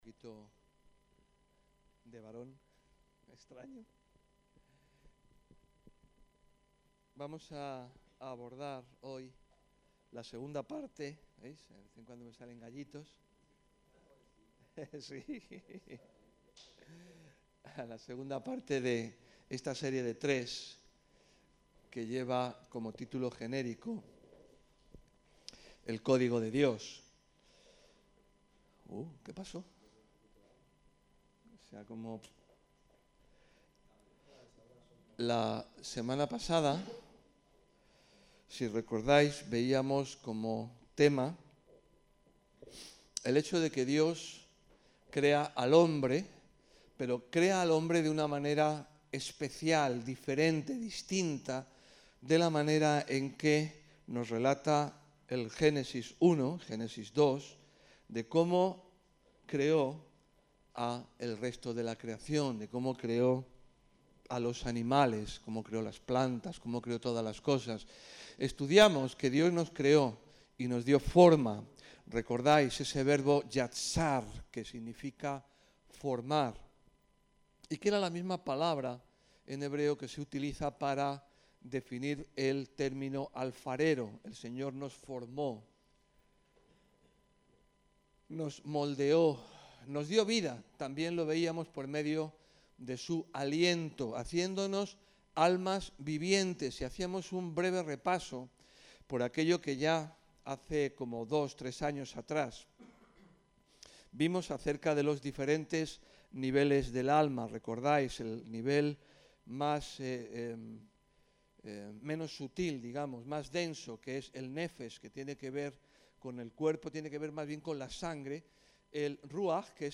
El soporte documental de la predicación es este: El Código de Dios – 02 El Nombre de Dios